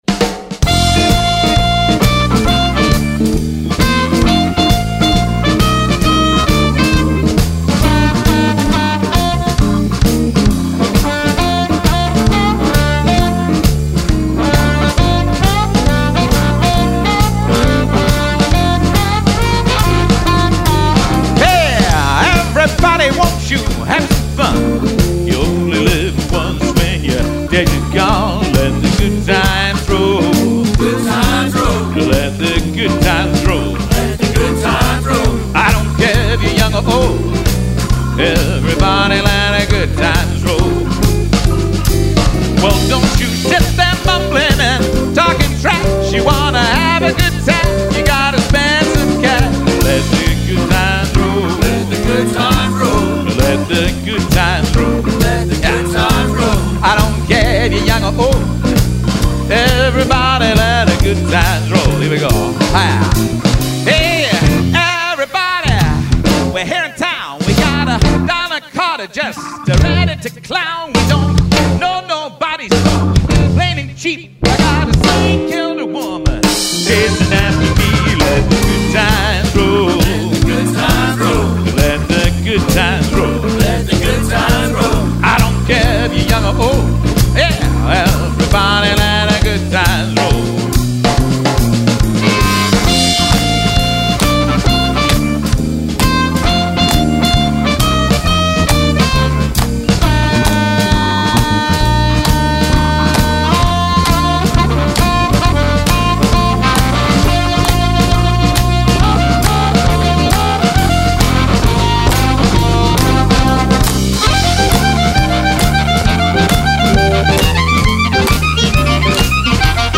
Telecaster
Pickups are Kinman Broadcaster® pickups (awesome!), and the guitar has Graphtek saddles and Sperzel locking tuners for solid intonation and tuning, and nice quick string changes.
Also, you can hear this guitar in a recent (2009) live studio recording with my '66 Ultratone